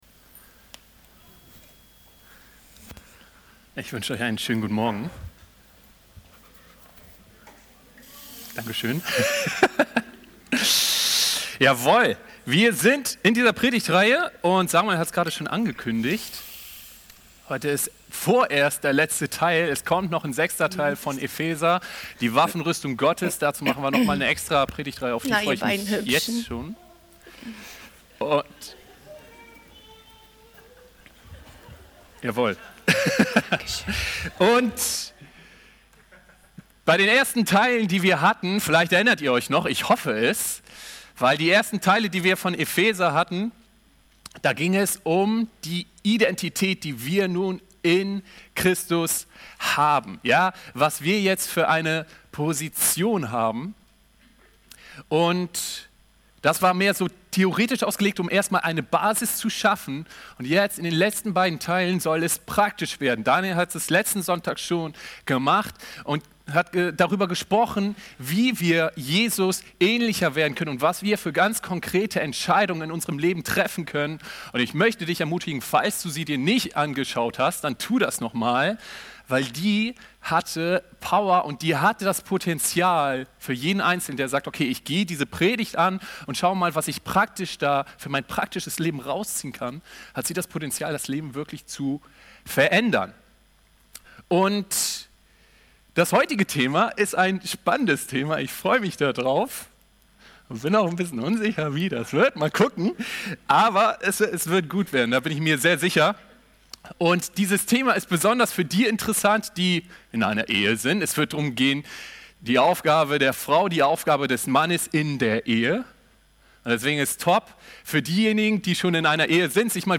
Ehe und Partnerschaft - FCG-Oldenburg Predigt-Podcast